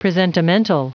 Prononciation du mot presentimental en anglais (fichier audio)
Prononciation du mot : presentimental